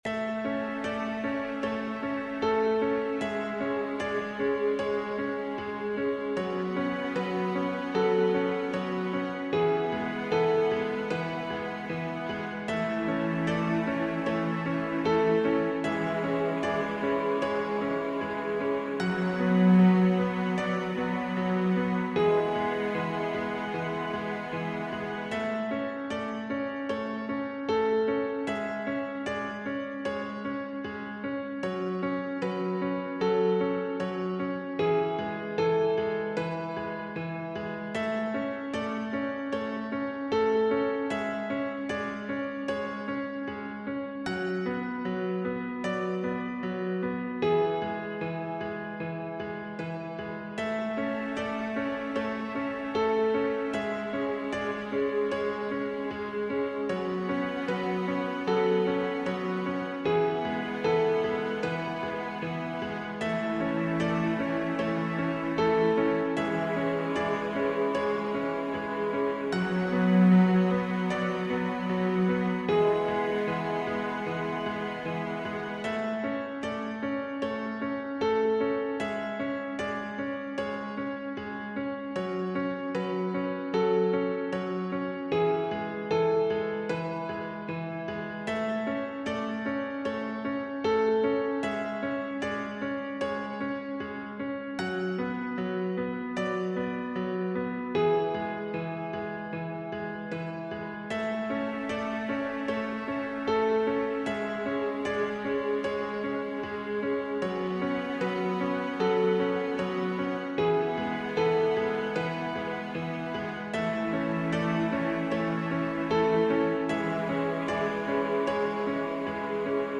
G – M Melancholy (TW)
Theater Music